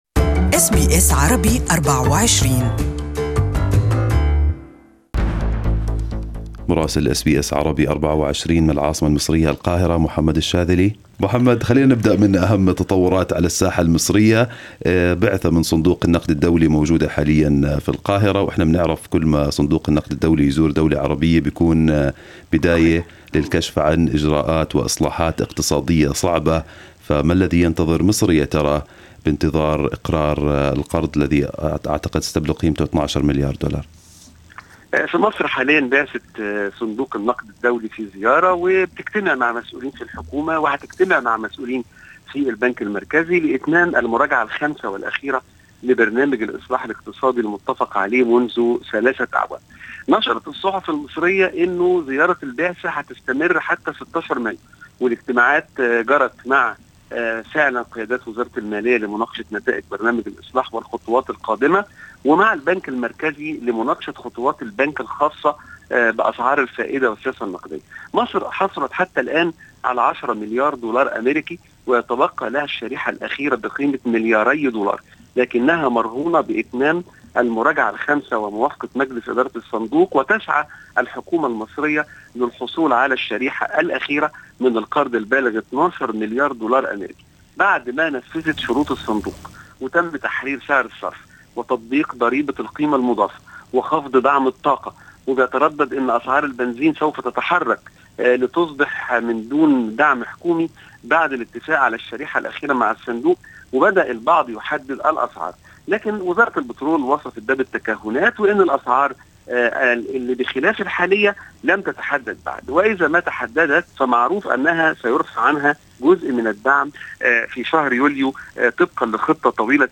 Our correspondent in Egypt has the details